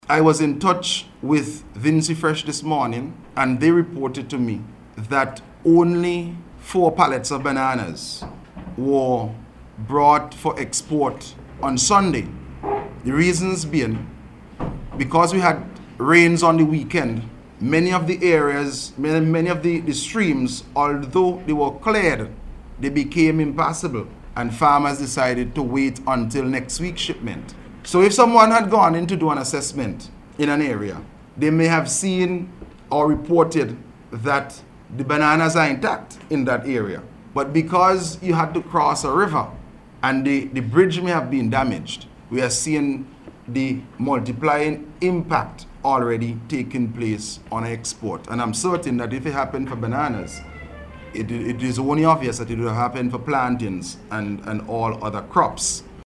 Speaking at a News Conference yesterday, Minister Caesar said the adverse weather has already affected exports of bananas.